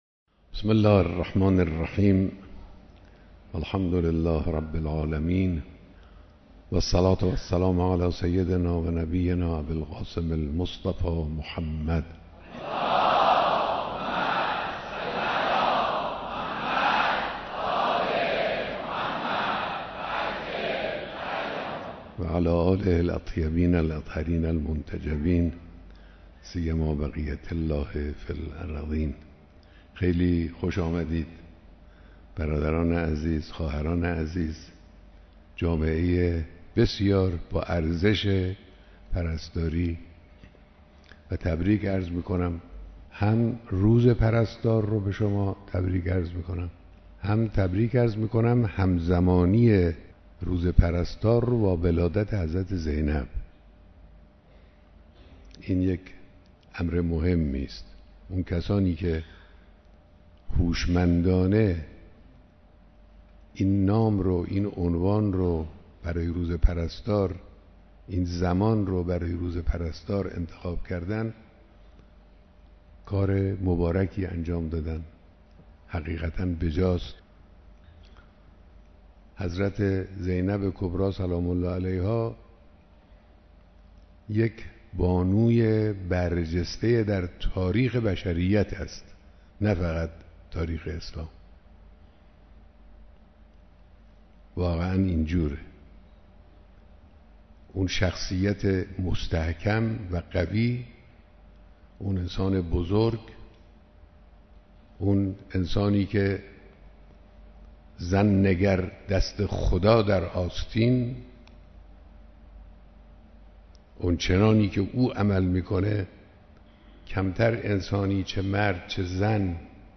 بیانات در دیدار هزاران نفر از پرستاران سراسر کشور